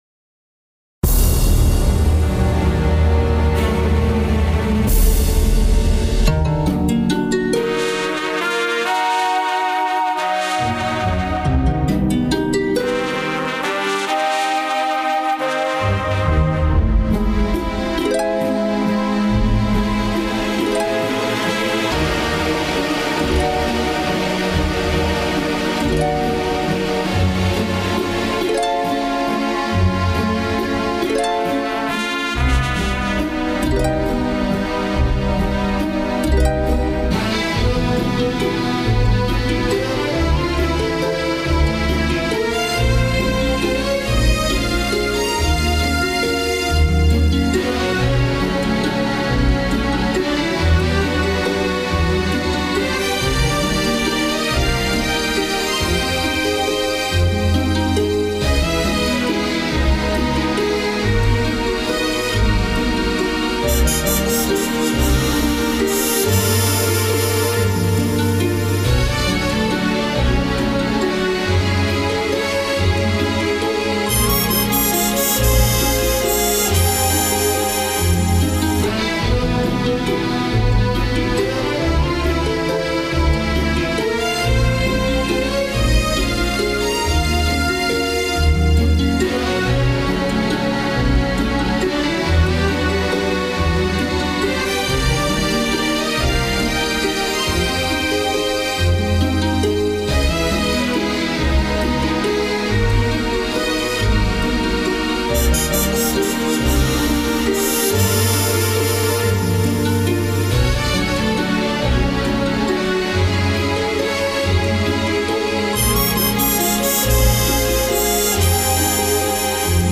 "ALASKA" | V.I.P. Symphony Orchestra + Alternative Version - Orchestral and Large Ensemble - Young Composers Music Forum